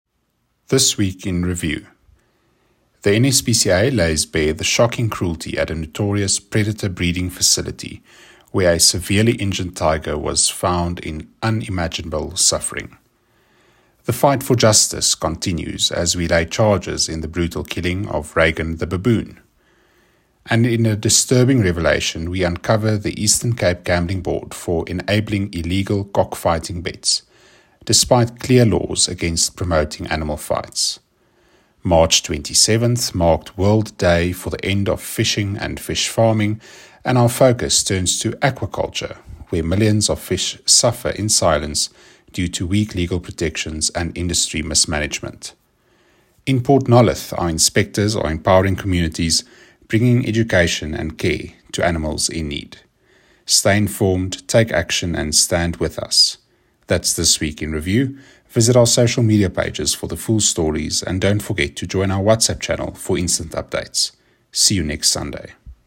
WiR-24-March-Voice-Over.mp3